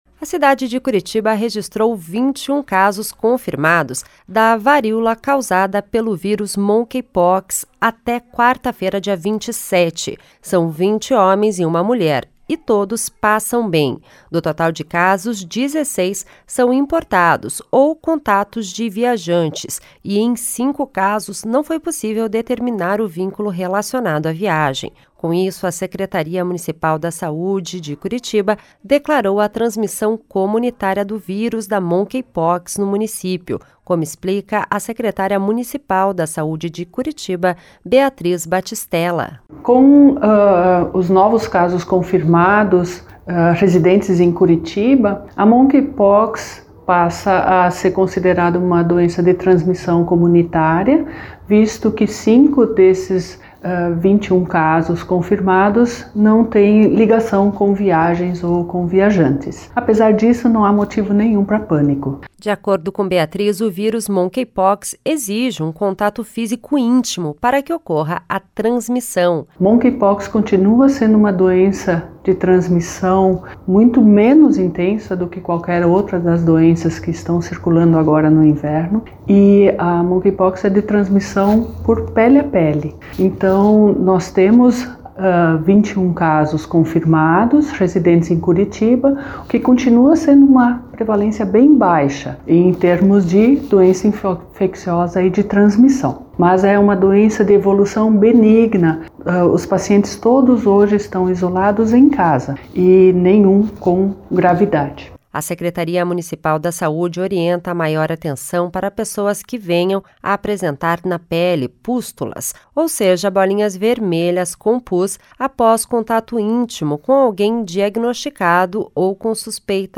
Com isso, a Secretaria Municipal da Saúde (SMS) de Curitiba declarou a transmissão comunitária do vírus da monkeypox no município, como explica a secretária municipal da Saúde de Curitiba, Beatriz Battistella.